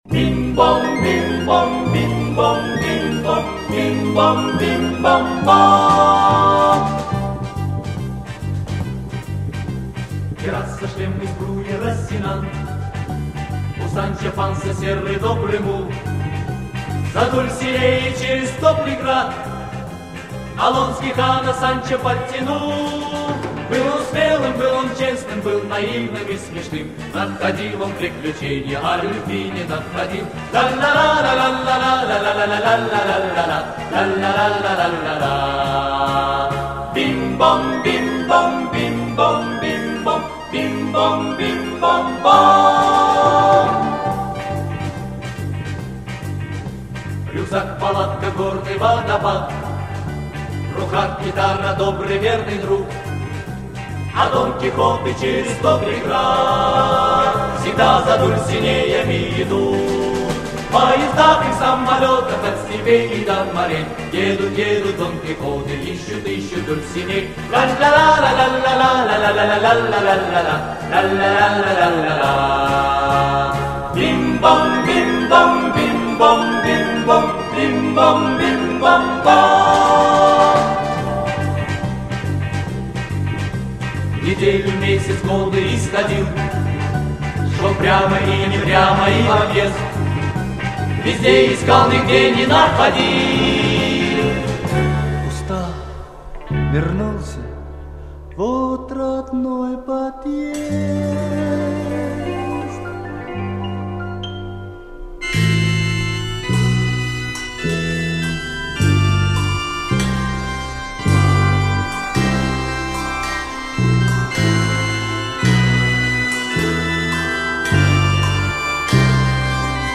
Запись произведена с эфира в октябре - декабре 1971 года.